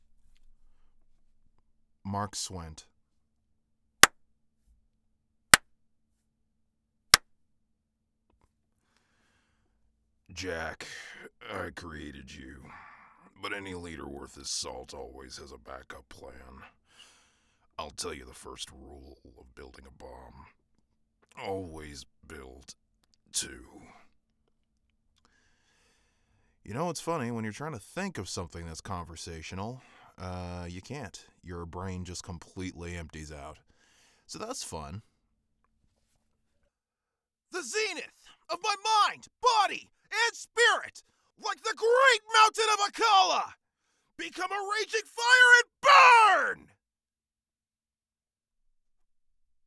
But how clean is the sound in there?
You can give the basic booth test a listen below or download it directly from the link.